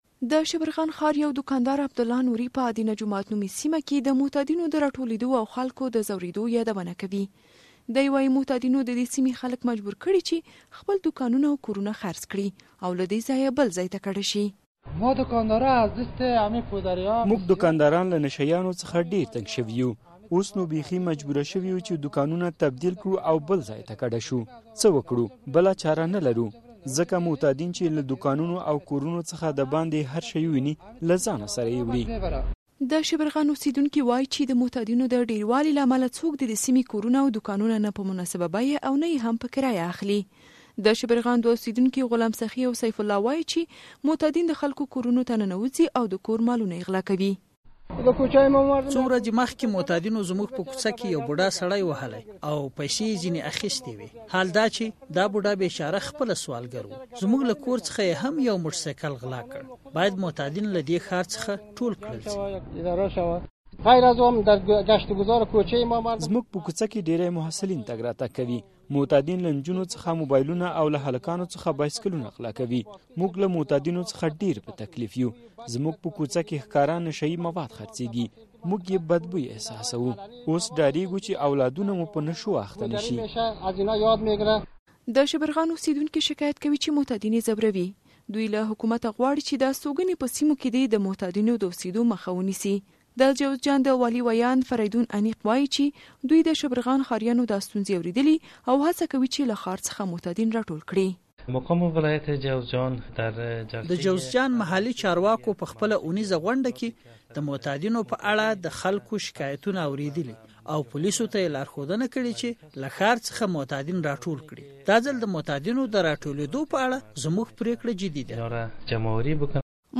د جوزجان راپور